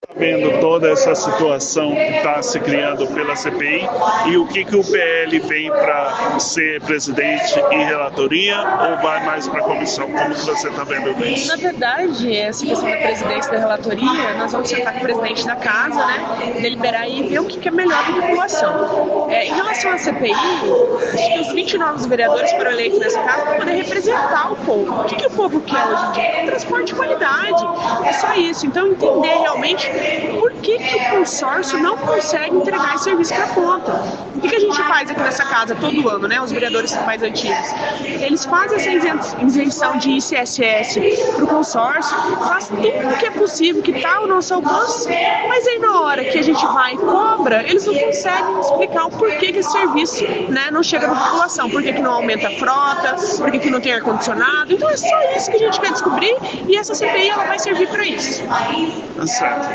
Em conversa com a nossa equipe durante a sessão de hoje, a vereadora Ana Portela (PL), sobre a comissão e o que ela espera dessa CPI.